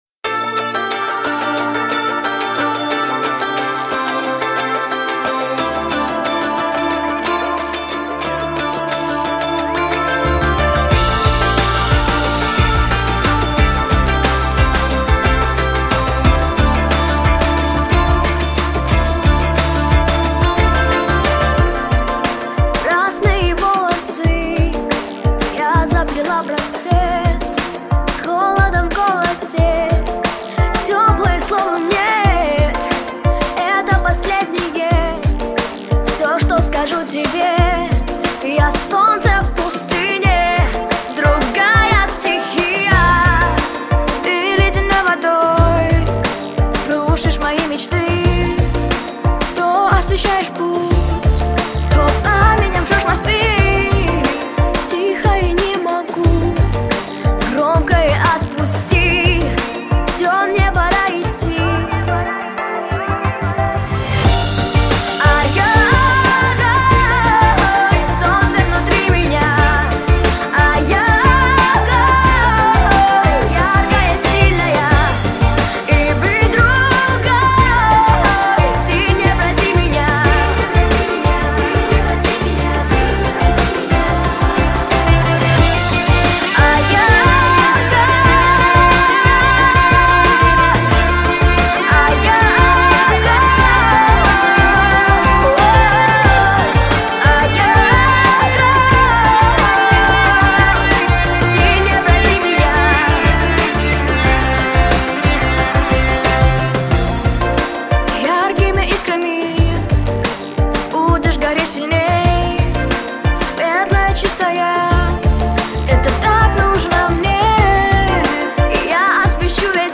• Качество: Хорошее
• Категория: Детские песни
подростковые песни